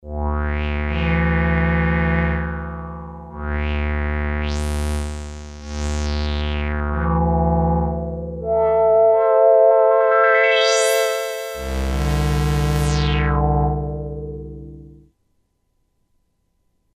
filter FILTER paraphonic analog lowpass filter with resonance (no self-oscillation) based on NJM2069 same found on synthesizer Korg Poly 800 and sampler DSS1.
demo Hear filter VCF